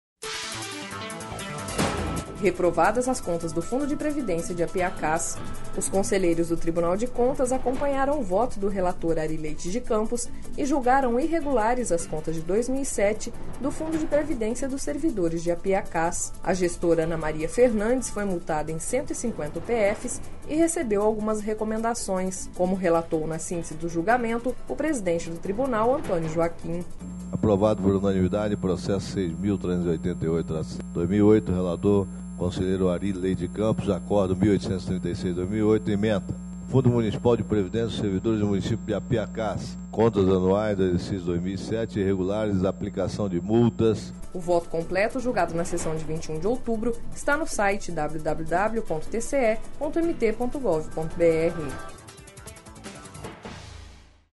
Sonora: Antonio Joaquim - conselheiro presidente do TCE-MT